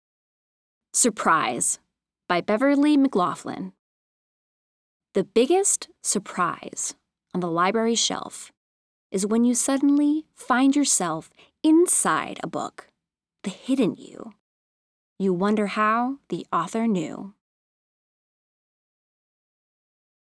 Story-Read-Aloud-Poem.mp3